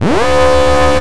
WARNING.wav